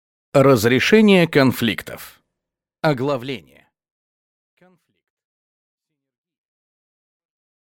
Аудиокнига Разрешение конфликтов | Библиотека аудиокниг